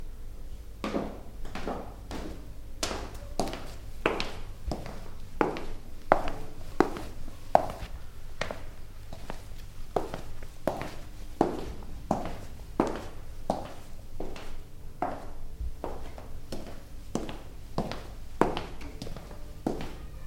在室内行走的脚步声 敲击声 敲击声 福里声
Tag: 拟音 脚步声 在室内 丝锥 攻丝 步行